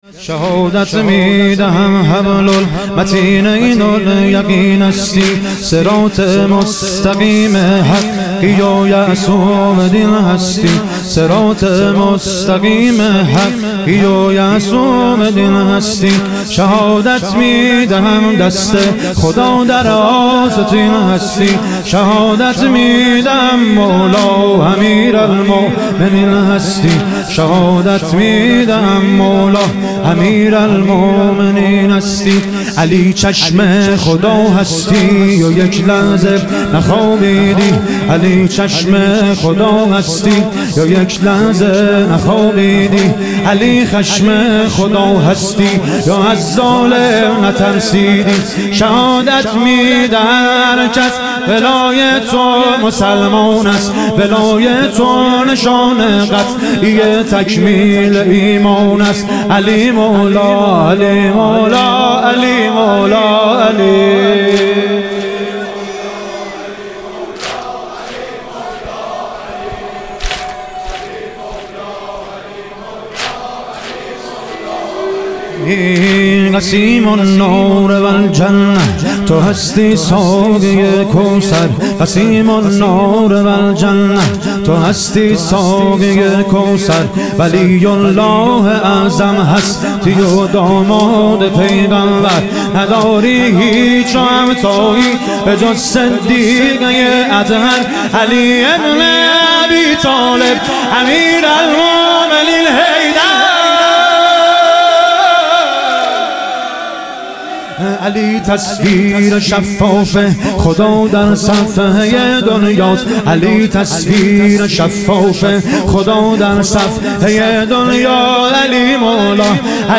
شهادت حضرت خدیجه علیها سلام - واحد